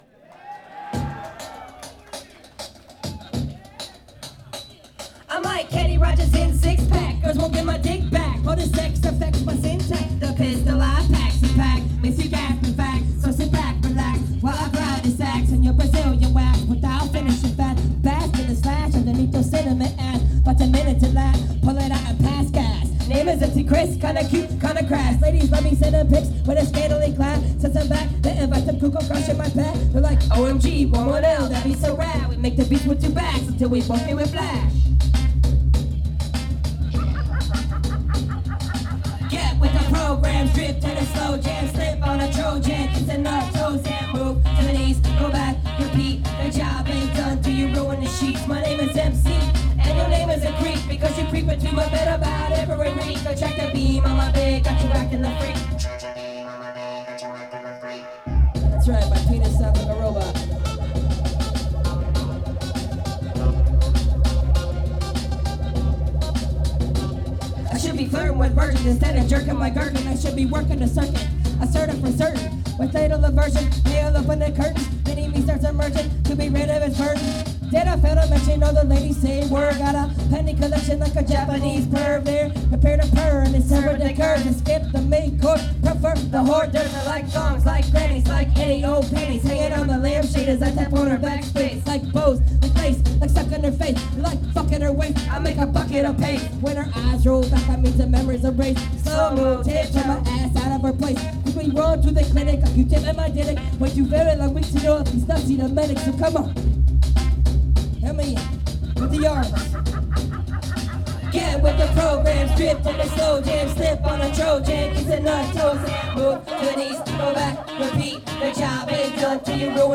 nashville, tn at the muse on february 9th 2005
soundboard feed and audience microphone matrix recording